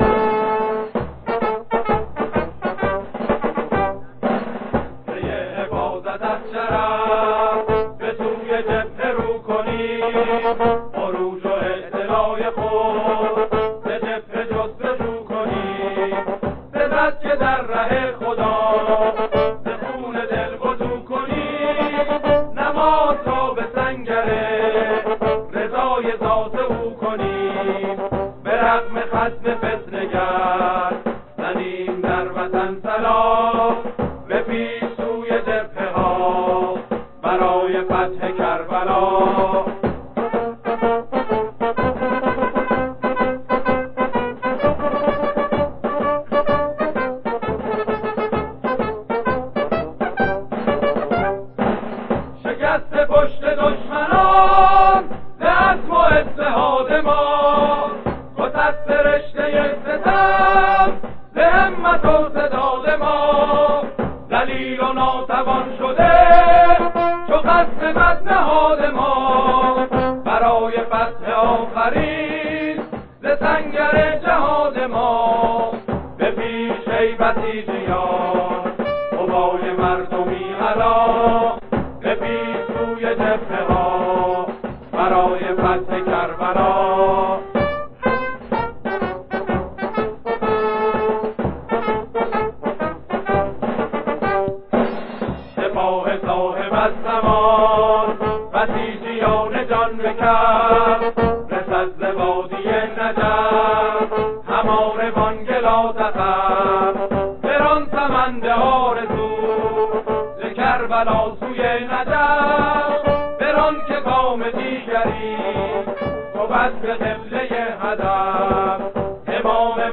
در باختران، قرارگاه نبی اکرم (ص) به اجرا درآورده است.